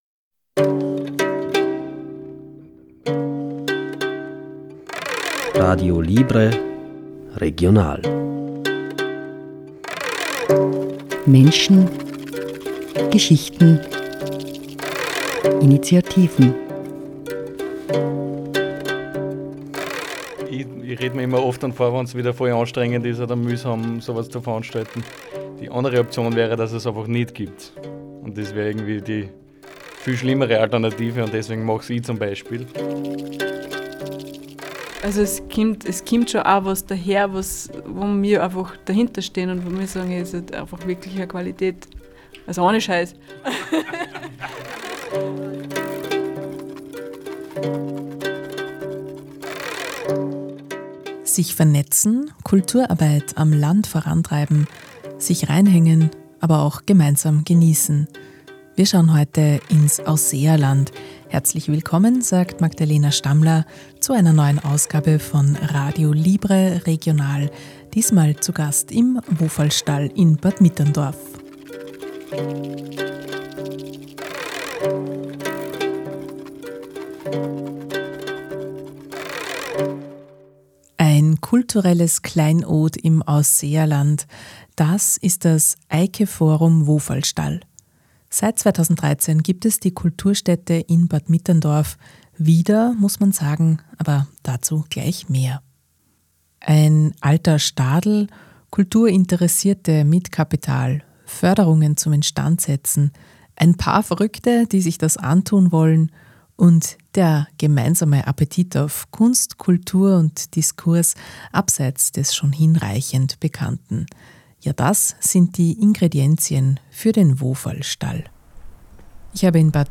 Die Kulturstätte in Bad Mitterndorf wird seit 2013 von einem Verein betrieben, dem wir einen Besuch abgestattet haben. Wem gehört der Stadel, wie wird er betrieben und was findet dort eigentlich statt?